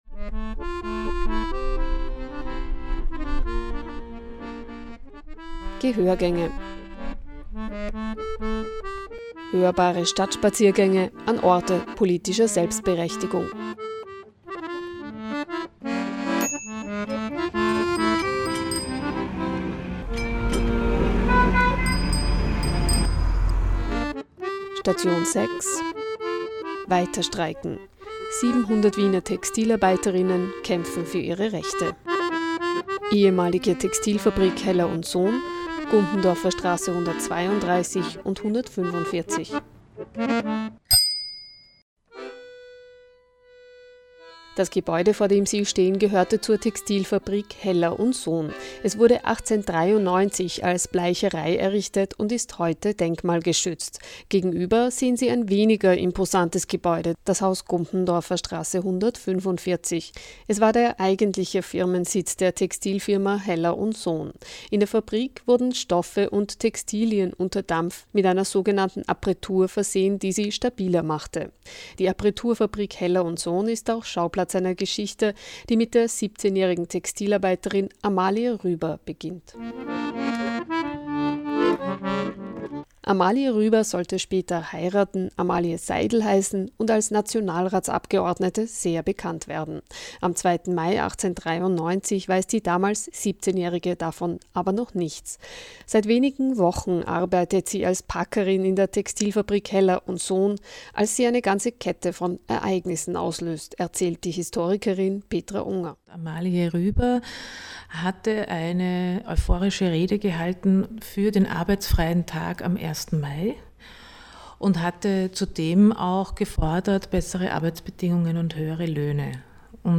Ein hörbarer Stadtspaziergang an Orte politischer Selbstberechtigung.